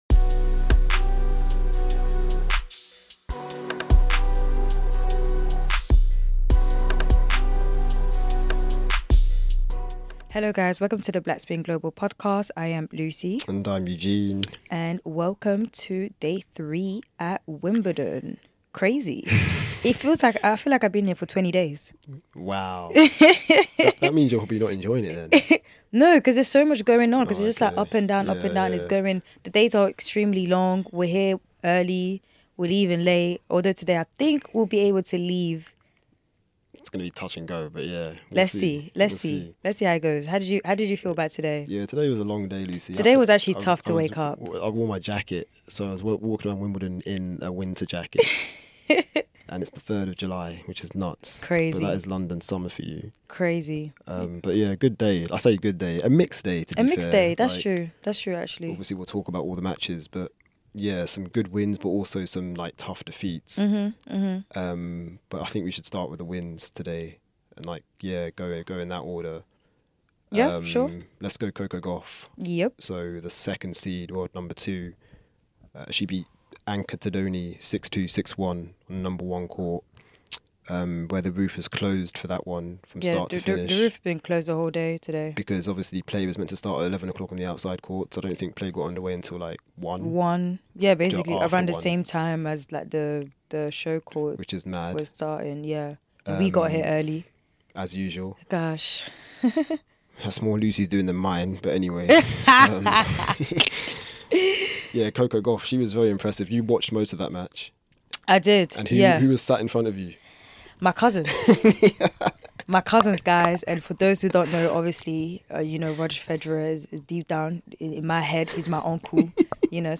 Listen out for interview snippets from Gauff, Tiafoe and Paolini. And to close we go through Day 4’s order of play where Madison Keys, Ben Shelton and Robin Montgomery among others all feature.